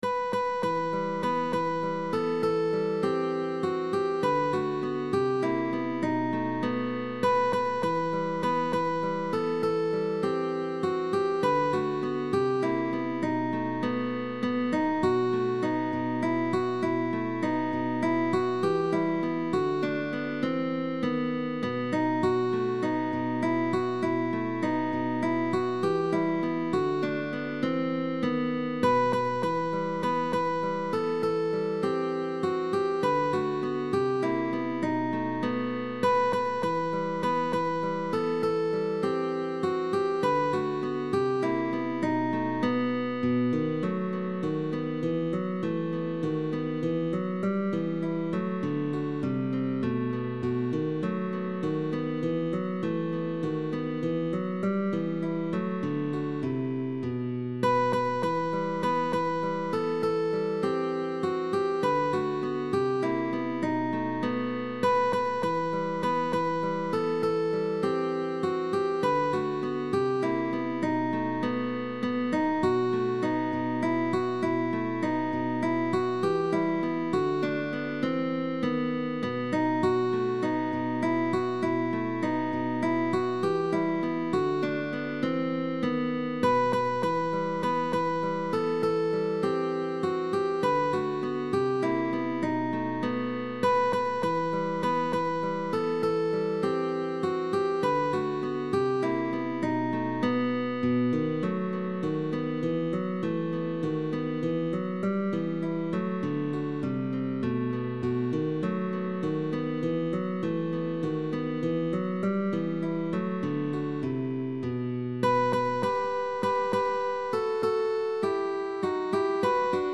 GUITAR DUO
traditional folk song and dance music of Andalusia
Arpeggios for three fingers (p,i, m)Thumb melody basss.